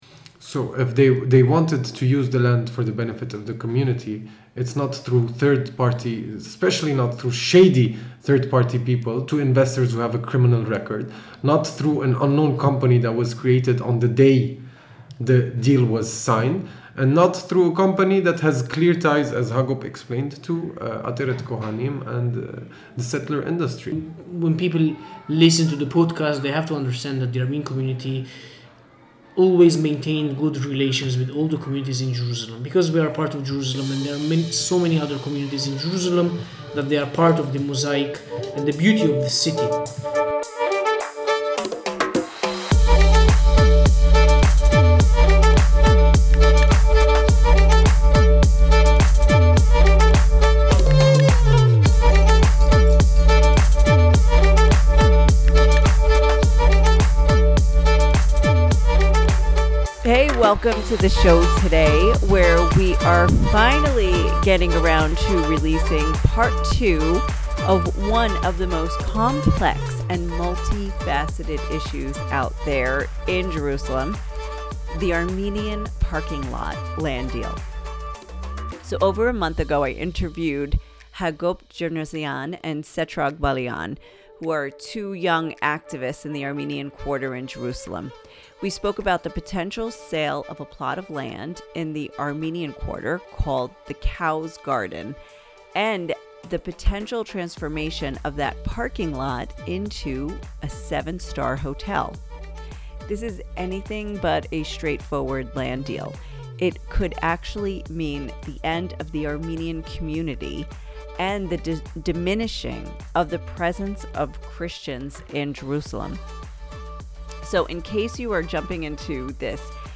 A controversial land deal involving the expansive Armenian parking lot has captured world attention. I interview two residents who have left the effort to save their community facing an existential threat.